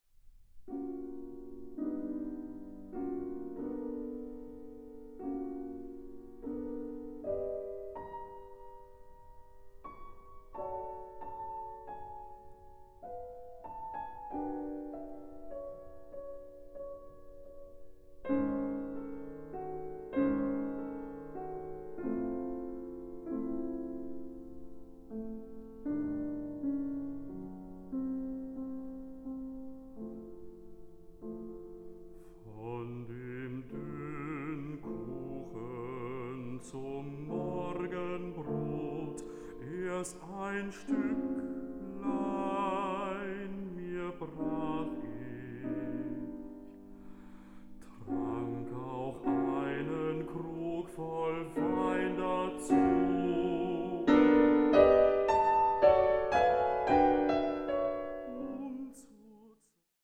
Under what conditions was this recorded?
Recording: Mendelssohn-Saal, Gewandhaus Leipzig, 2025